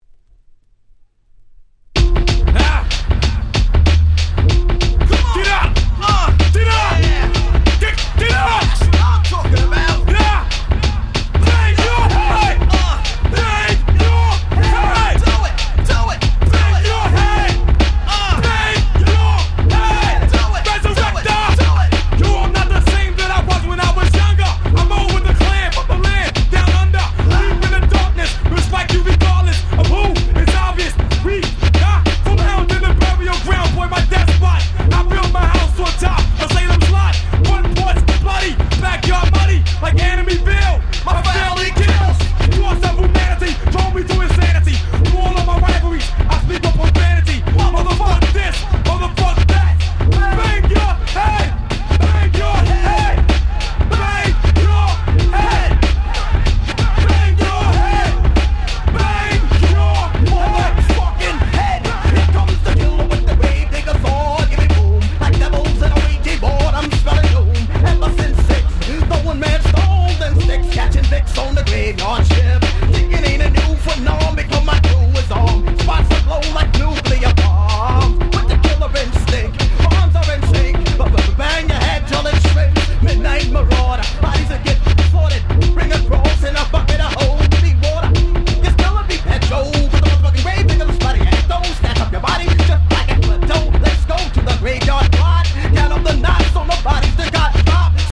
95' Very Nice Hip Hop EP !!
浮遊感のある上物が何とも堪らない最強にDopeな1曲！！
これぞ90's Hip Hop !!